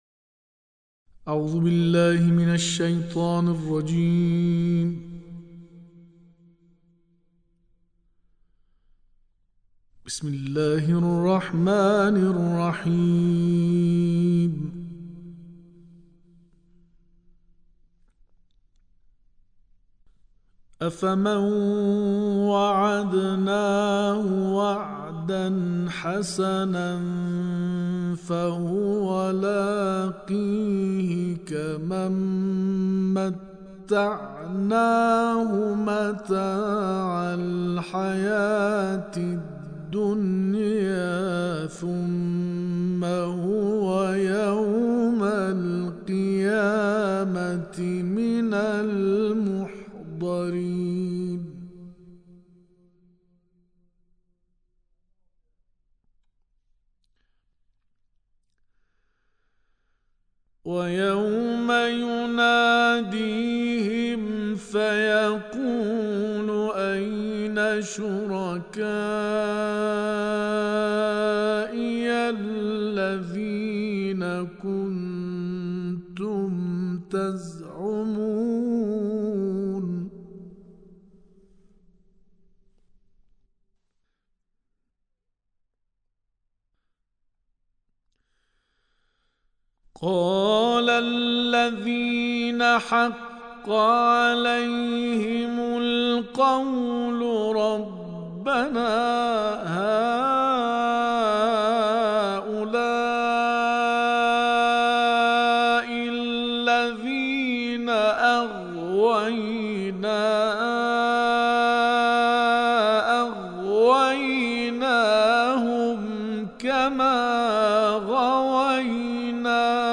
ارائه تلاوت آموزشی برای افراد فاقد بم قوی + دانلود
تلاوت آموزشی از سوره قصص